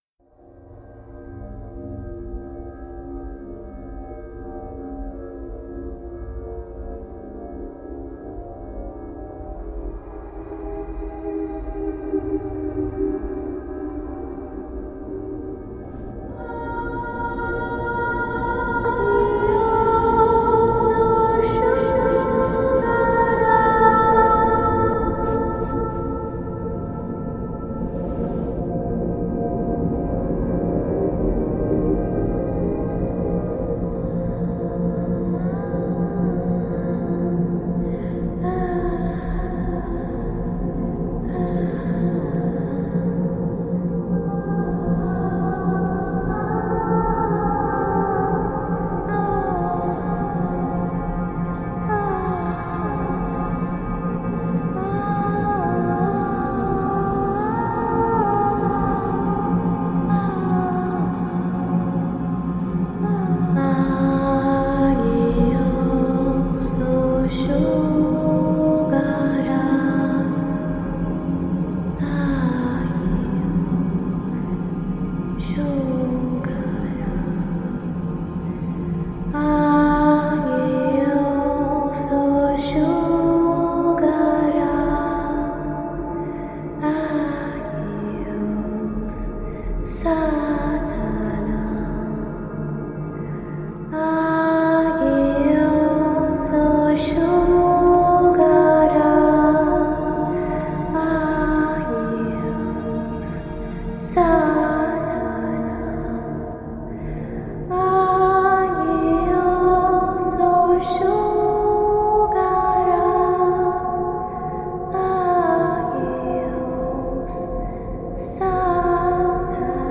dandoci così un’impronta più sacrale e malinconica.
Strumenti: Pianoforte e Synth
Voce growl
doveva richiamare l’idea di un lunapark degli orrori